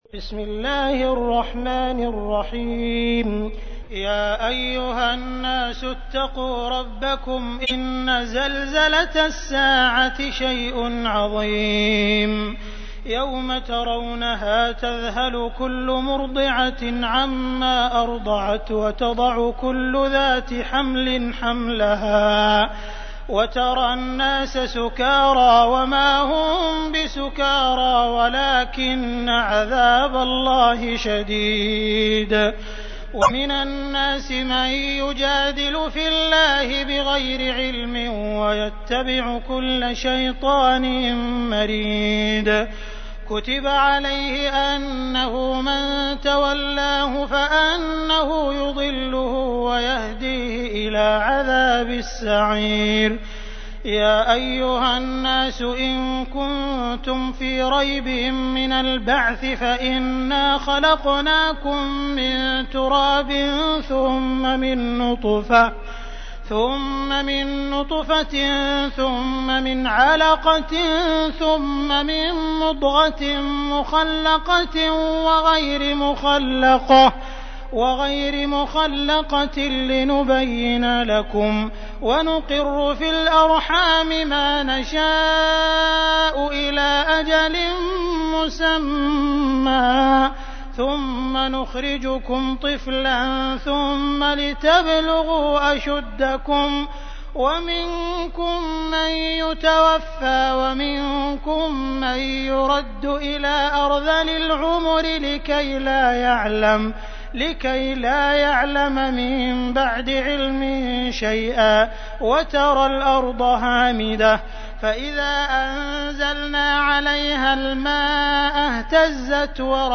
تحميل : 22. سورة الحج / القارئ عبد الرحمن السديس / القرآن الكريم / موقع يا حسين